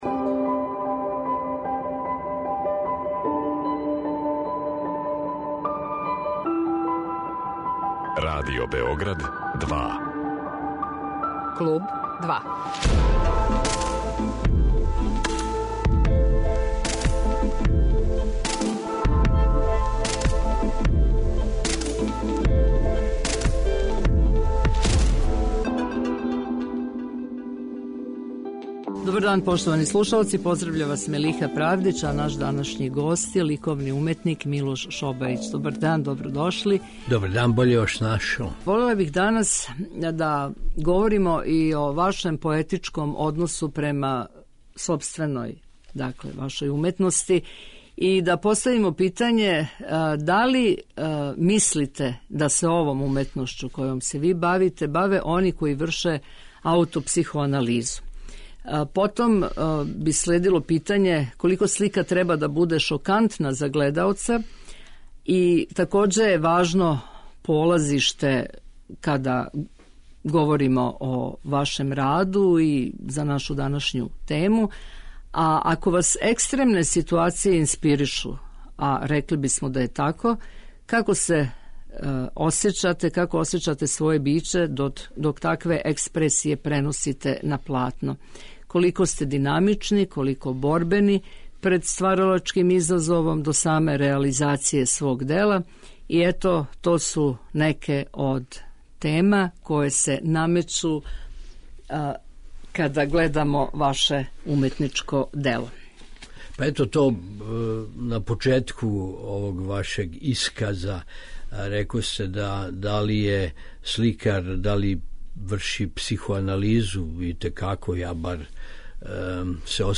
Гост 'Клуба 2' је ликовни уметник Милош Шобајић